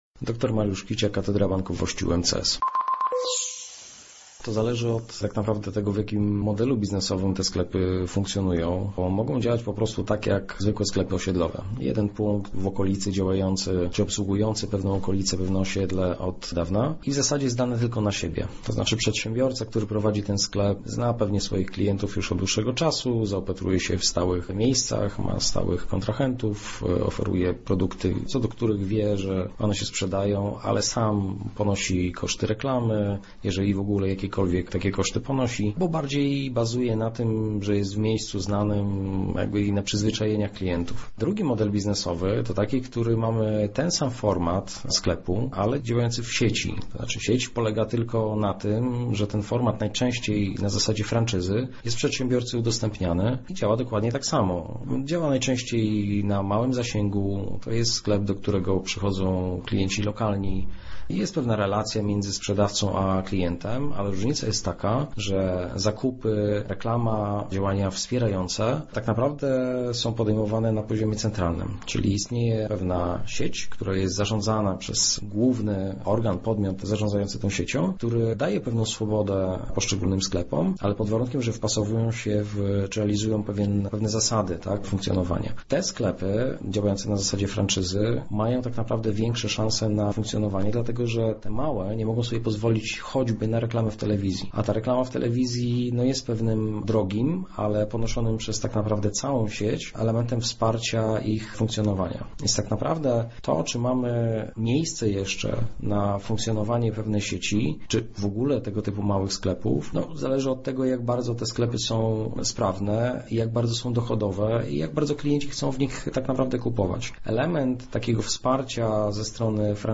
ekonomista.mp3